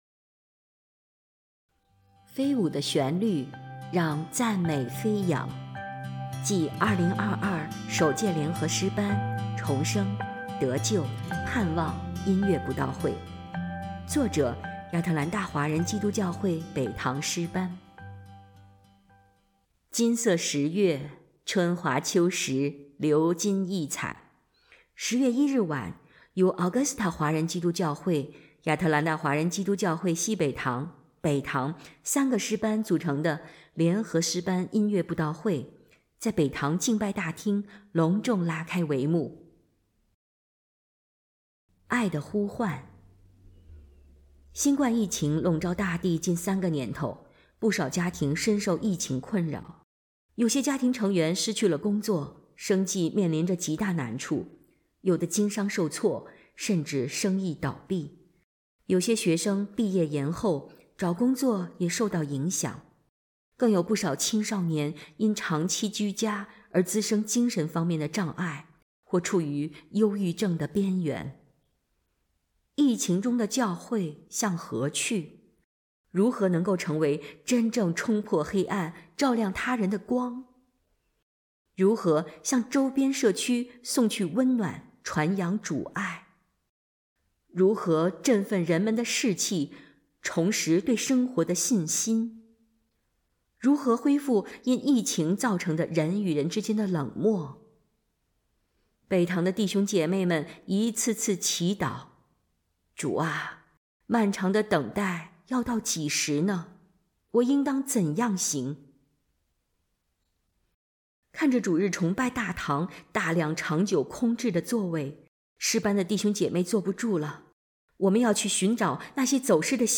音频朗读